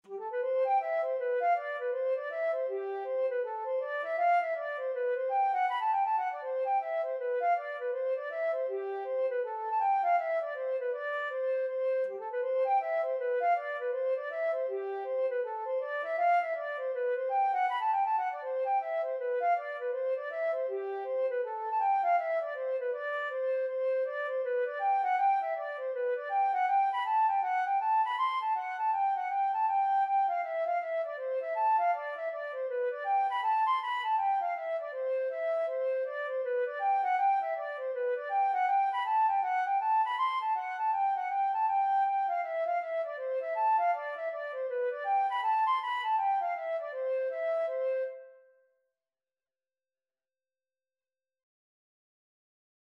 C major (Sounding Pitch) (View more C major Music for Flute )
4/4 (View more 4/4 Music)
G5-C7
Flute  (View more Intermediate Flute Music)
Traditional (View more Traditional Flute Music)
coeys_hornpipe_ON1604_FL.mp3